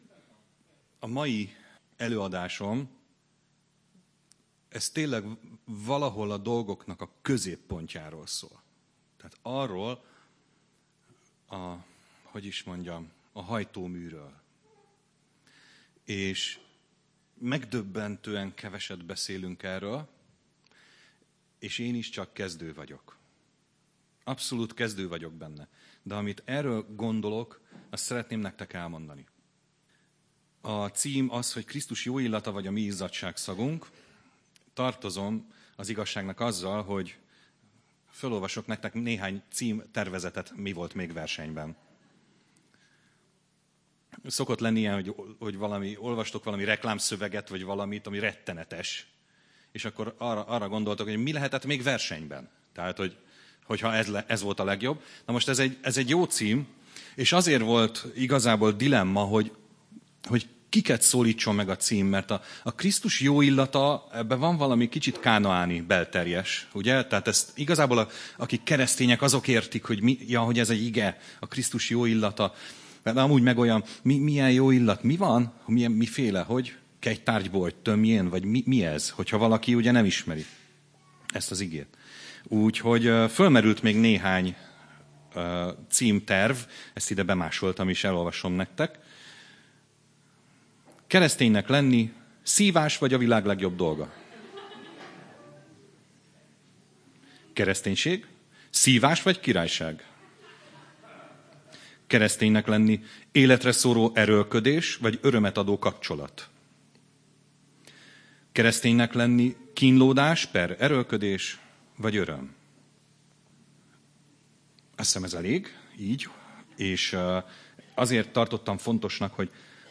Előadások 2018-ban